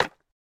resin_brick_place1.ogg